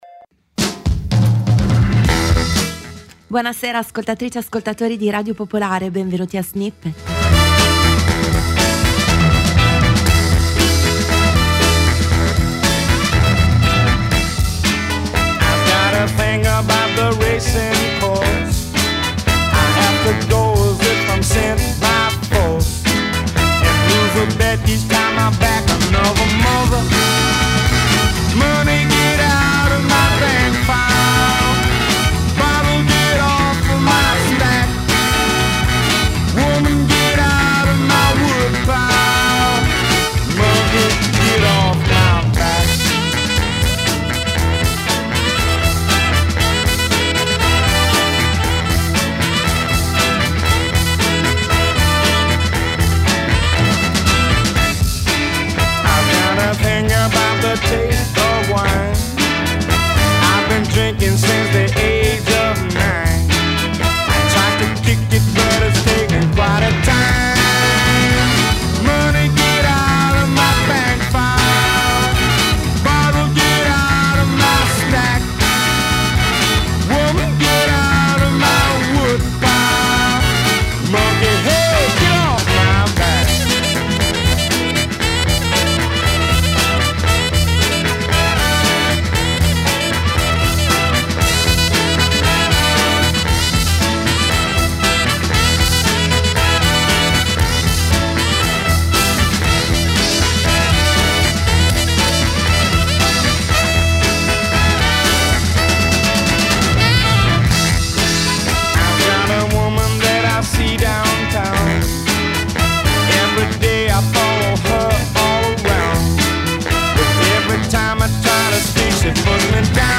attraverso remix, campioni, sample, cover, edit, mash up.